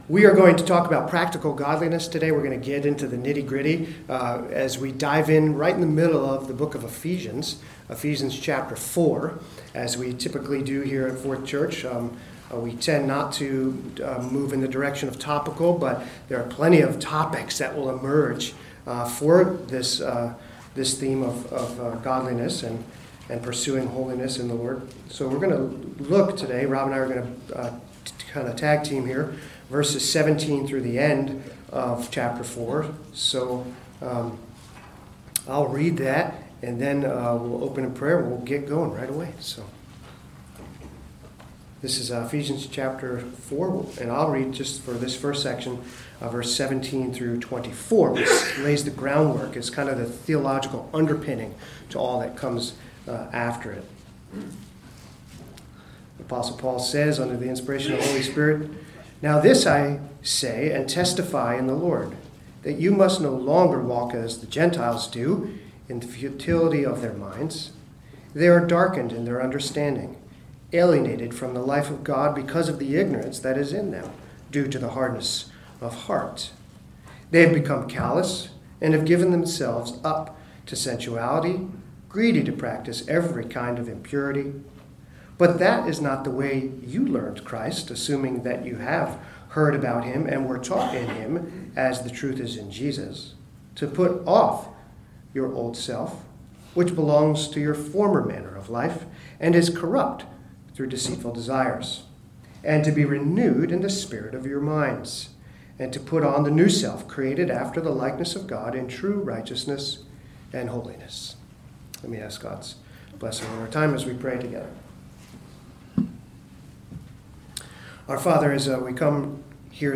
2019 Men’s Conference: Practical Godliness The Challenge of Christian Discipleship
Class